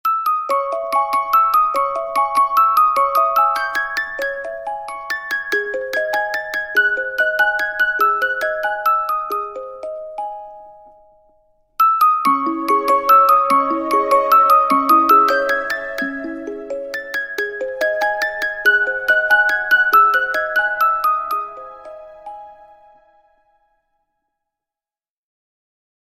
• Качество: 128, Stereo
спокойные
без слов
Electronica
волшебные
колокольчики
звонкие
Музыкальная шкатулка